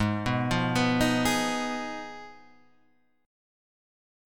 G#m6 chord {4 2 3 4 4 4} chord